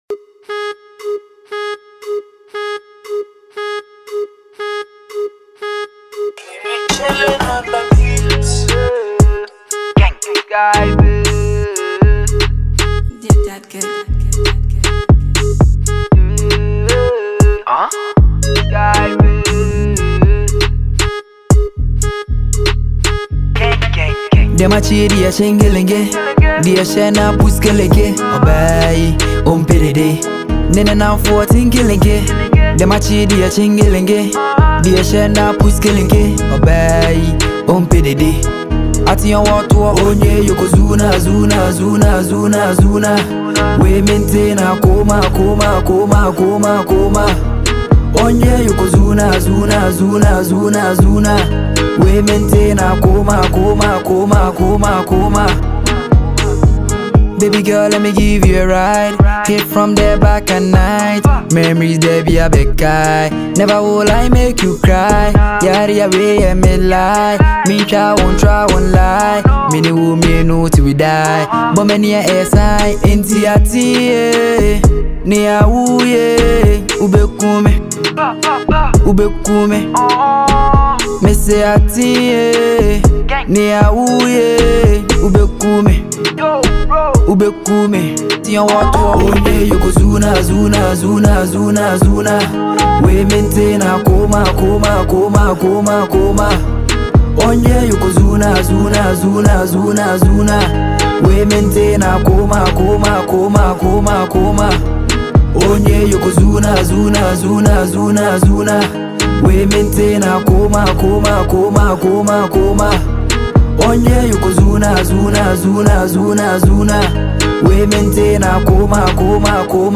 a Ghanaian asakaa rapper
This is a banger all day.